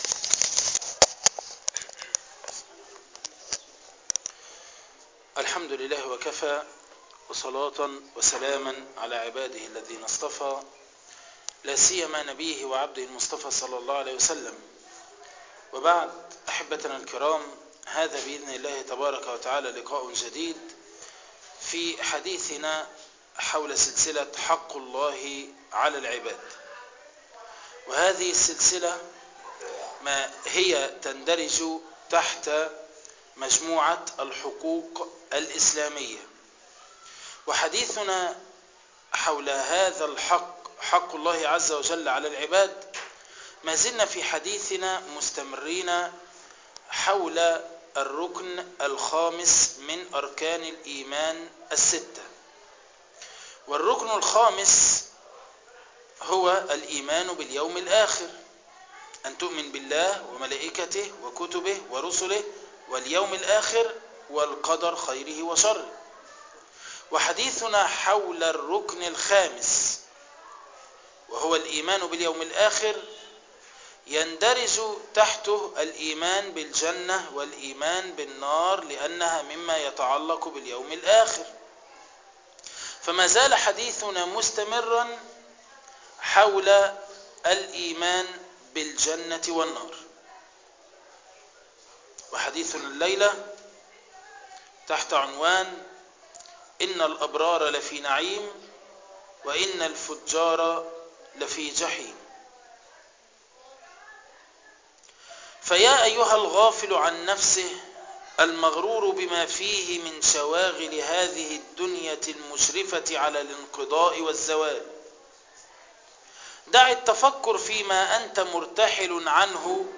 حق الله على العباد الدرس الرابع والعشرون